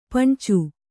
♪ paṇcu